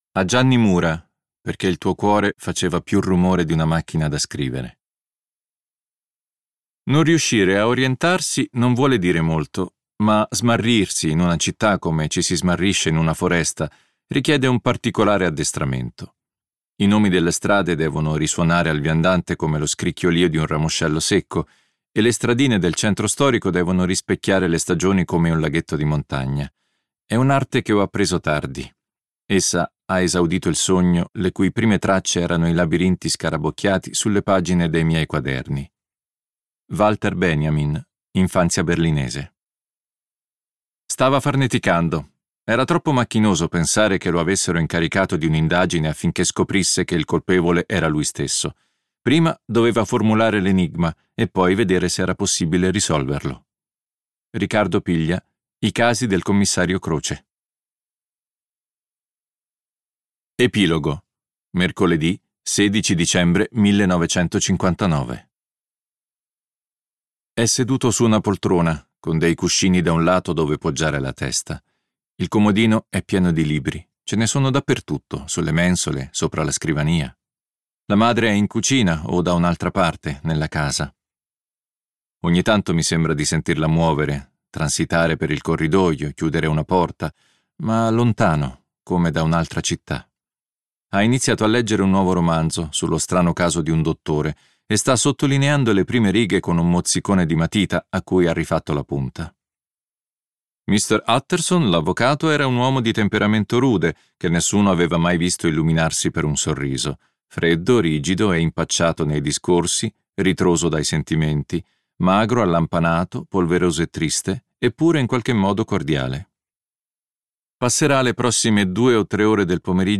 letto da Neri Marcorè
Versione audiolibro integrale